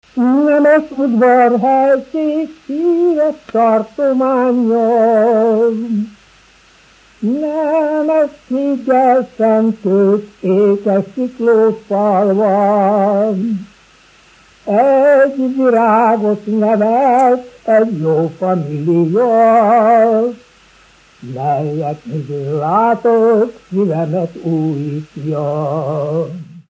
Erdély - Udvarhely vm. - Siklód
ének
Műfaj: Virágének
Stílus: 4. Sirató stílusú dallamok
Kadencia: 5 (4) 1 1